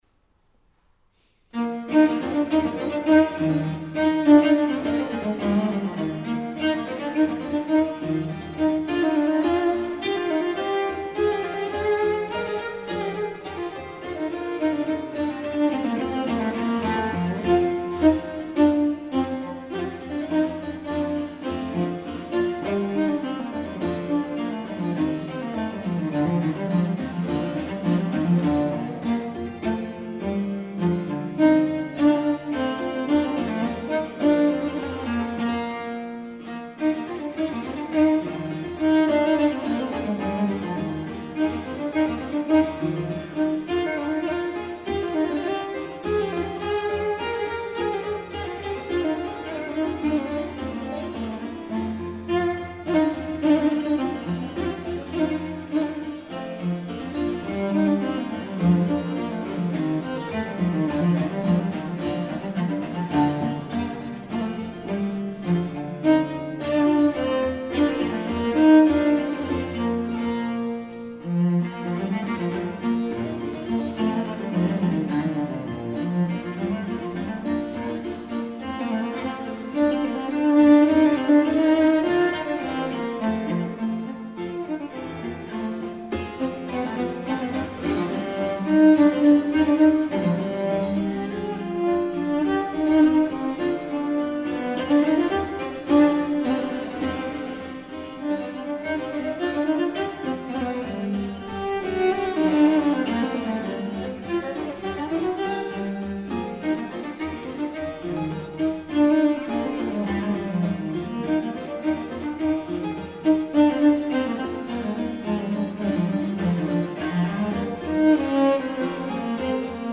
Registrazioni LIVE
Chiesa di Sant'Agostino - Rovigo, 11 settembre 2015
Christophe Coin Violoncello
cembalo